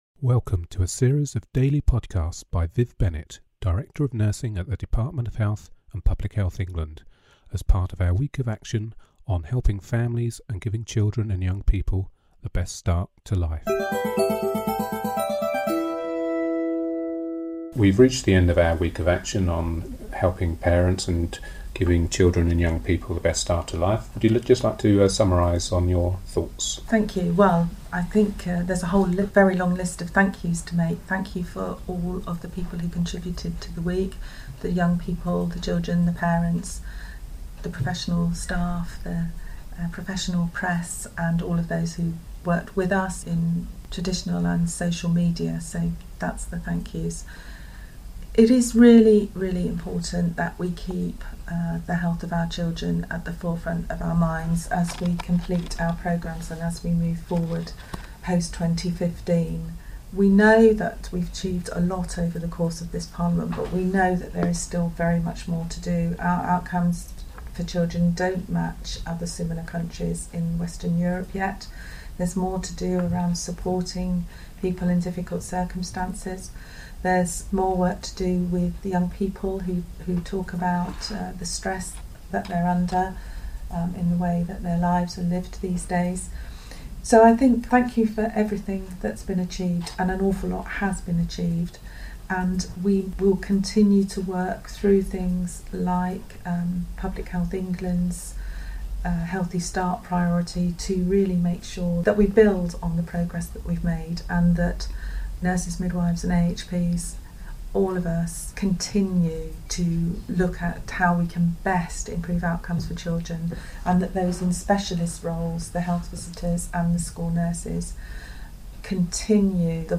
Part of a series of daily podcast in which Viv Bennett, Director of Nursing at the Department of Health and Public Health England, talks about the development of the health visitor programme.